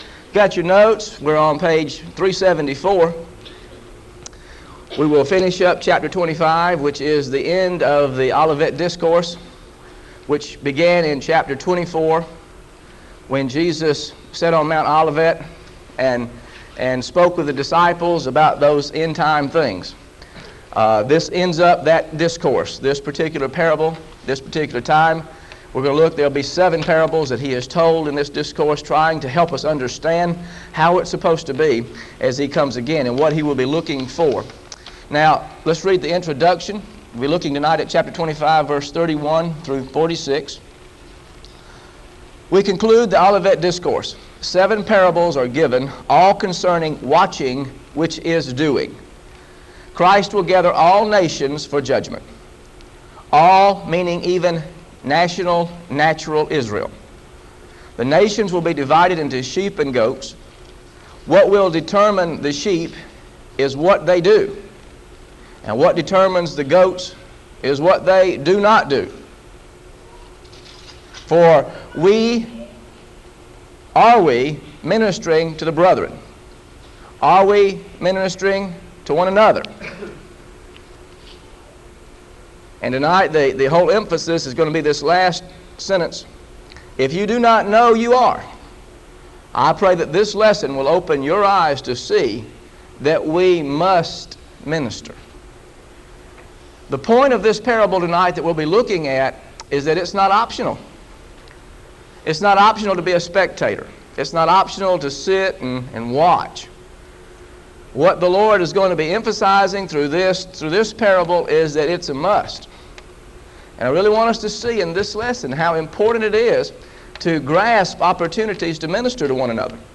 GOSPEL OF MATTHEW BIBLE STUDY SERIES This study of Matthew: Matthew 25 Verses 31-46 is part of a verse-by-verse teaching series through the Gospel of Matthew.